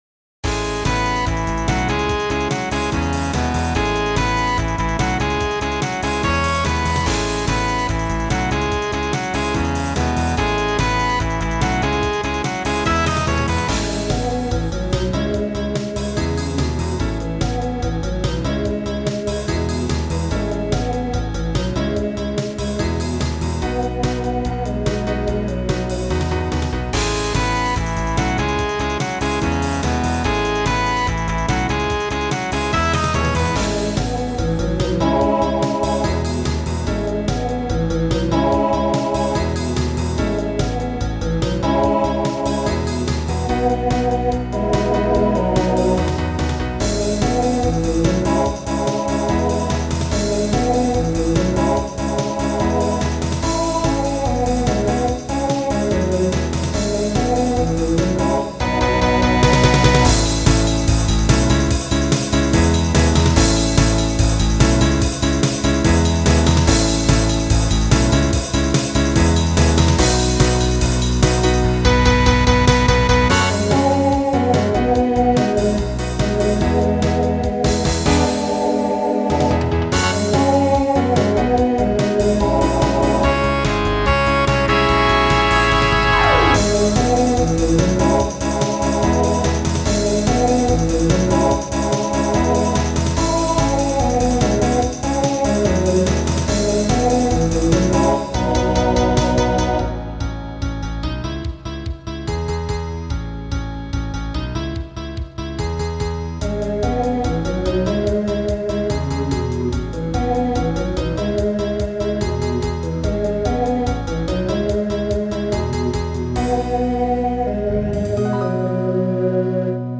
Voicing TTB Instrumental combo Genre Rock